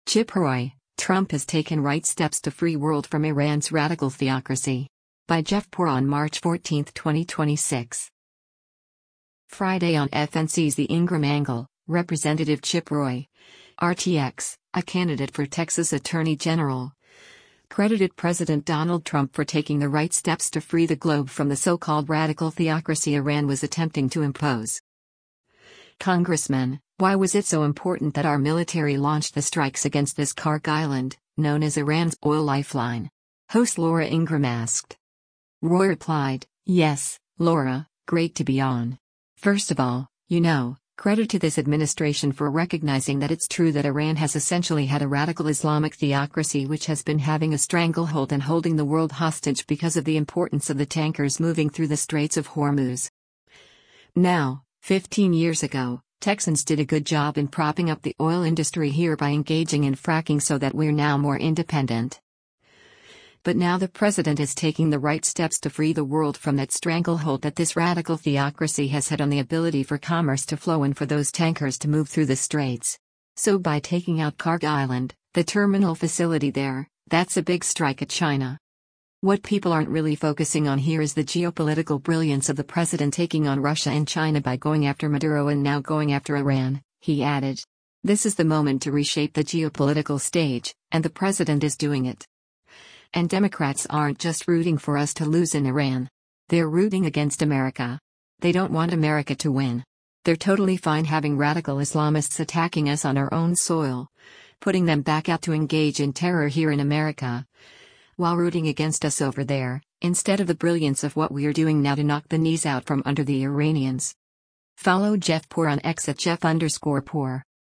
Friday on FNC’s “The Ingraham Angle,” Rep. Chip Roy (R-TX), a candidate for Texas Attorney General, credited President Donald Trump for taking the right steps to free the globe from the so-called “radical theocracy” Iran was attempting to impose.
“Congressman, why was it so important that our military launched the strikes against this Kharg Island, known as Iran’s oil lifeline?” host Laura Ingraham asked.